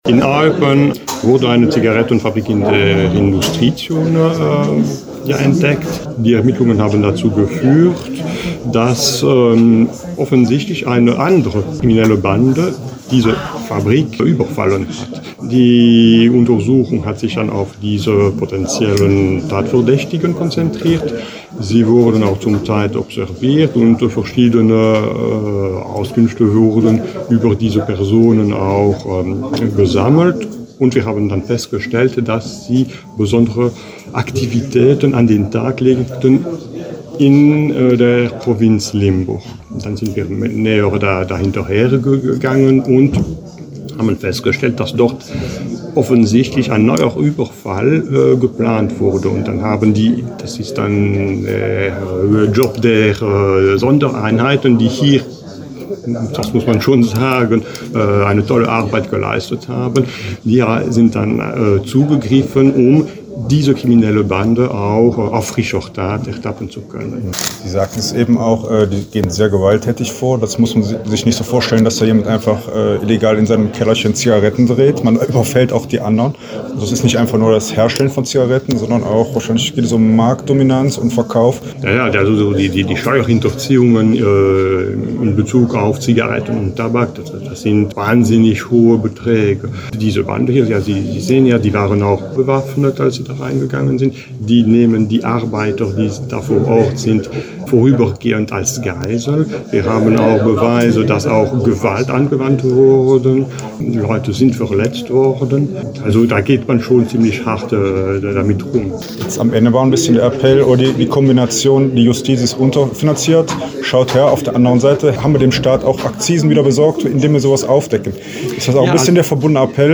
mit Staatsanwalt Frédéric Renier gesprochen